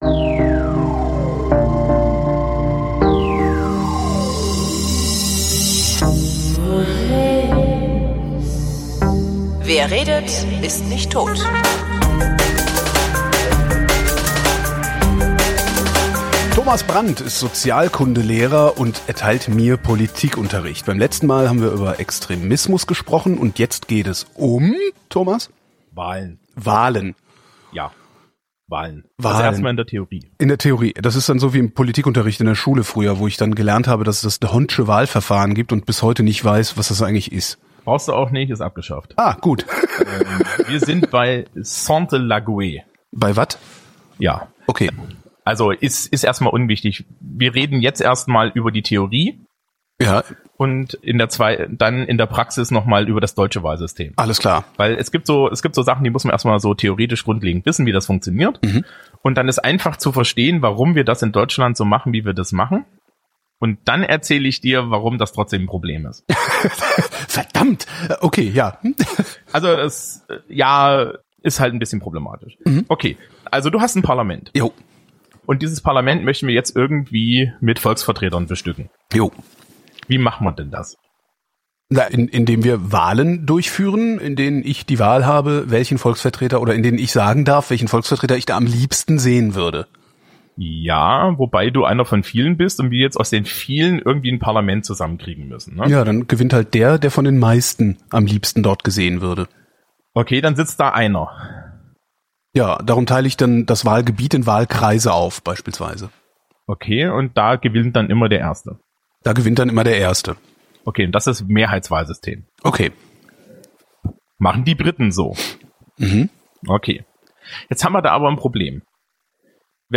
Darum haben wir das Ende der Sendung im Novermber 2024 neu aufgenommen).